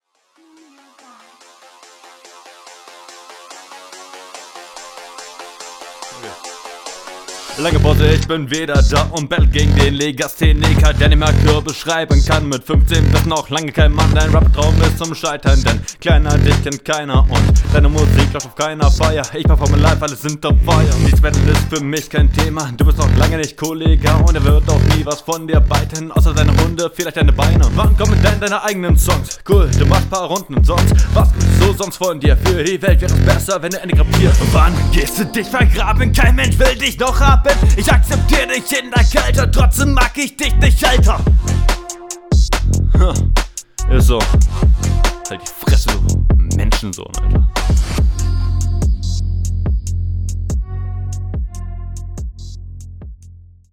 Flow: Besser als deine RR, aber doch etwas am stolpern an manchen Stellen.